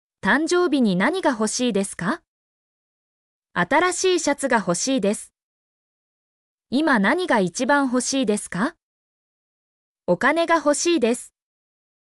mp3-output-ttsfreedotcom-3_IPk5ABPh.mp3